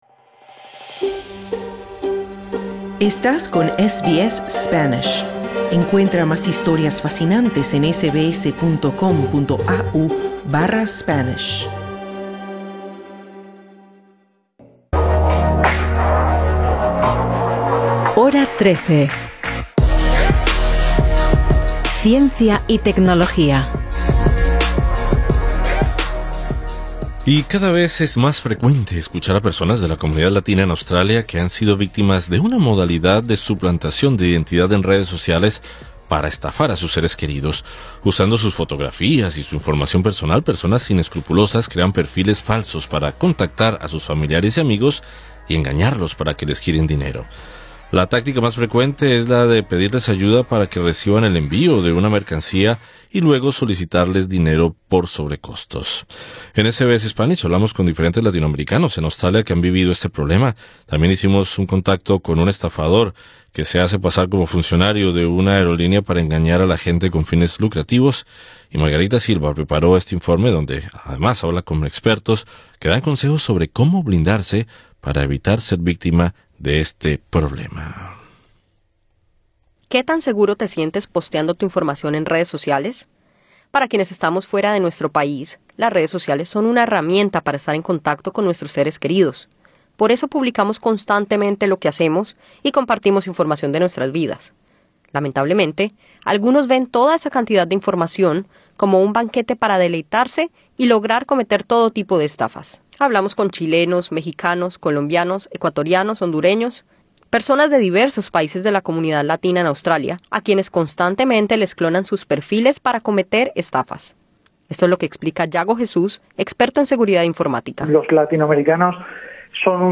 Te explicamos en qué consiste la “clonación” o suplantación de perfiles en redes sociales para cometer estafas, una práctica que está impactando a cada vez más hispanohablantes en Australia. En SBS Spanish grabamos una llamada con un estafador que simula ser funcionario de una aerolínea, hablamos con varios afectados y consultamos a expertos para conocer qué medidas de seguridad existen para evitar ser una víctima más.